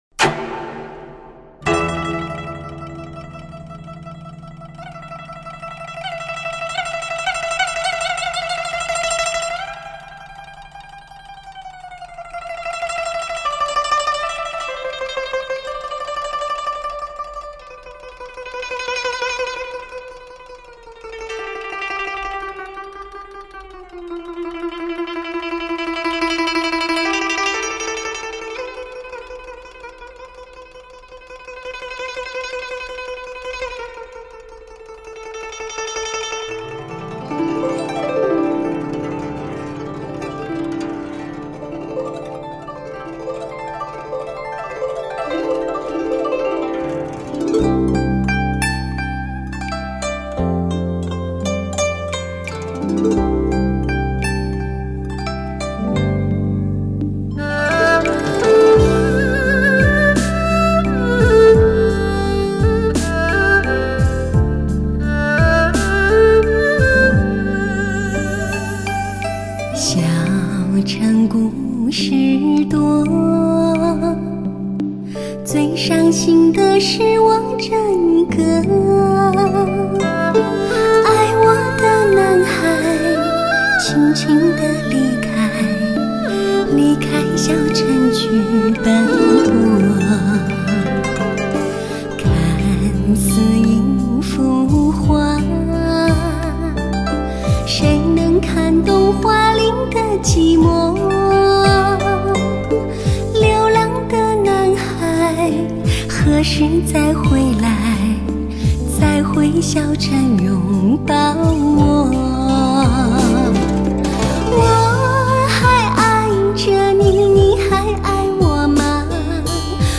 一场以流行歌曲为主角的发烧音乐盛宴，大师级制作展现强烈的空间感，极佳的乐器分离度，浑厚、甜润的人声质感，实在听得过瘾！